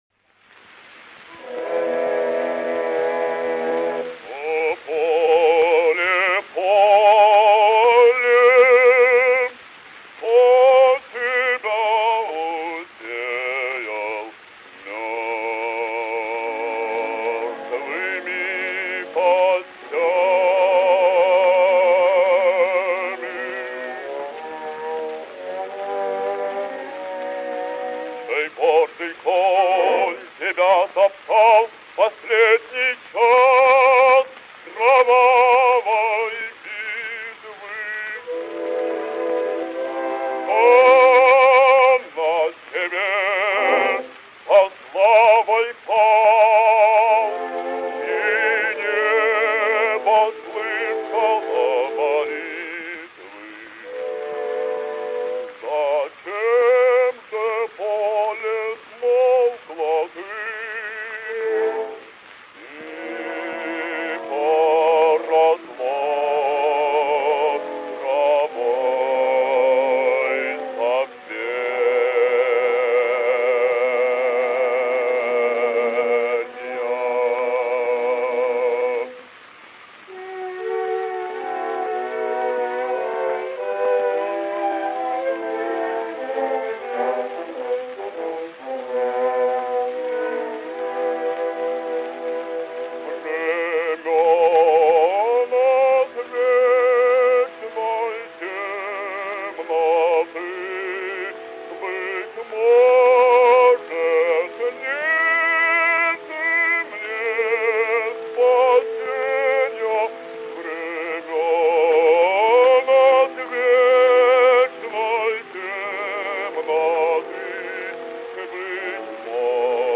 Василий Петров - Ария Руслана (М.И.Глинка. Руслан и Людмила)